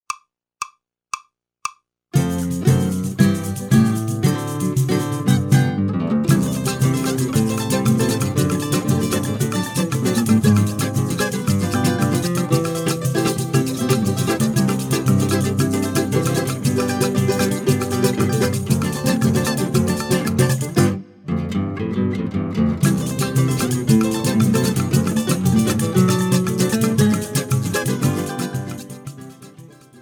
Playalongs da música instrumental brasileira.
Ou este excitante choro